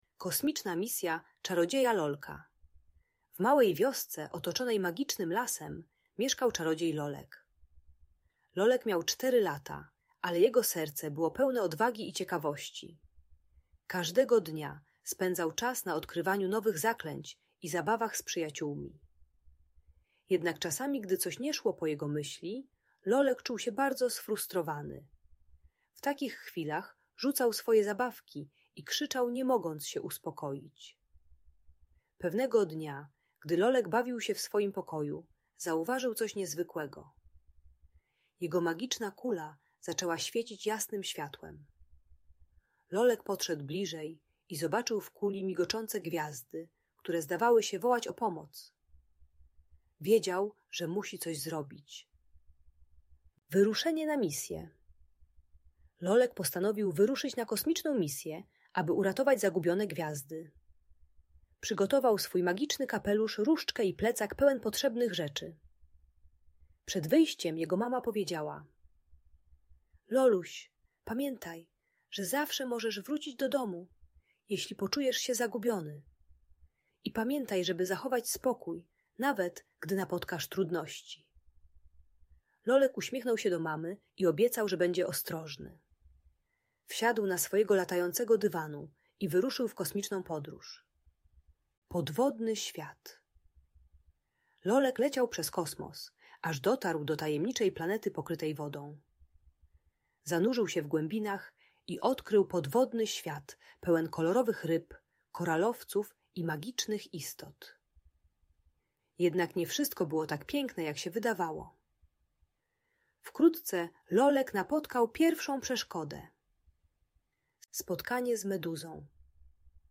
Kosmiczna misja Lolka - Bunt i wybuchy złości | Audiobajka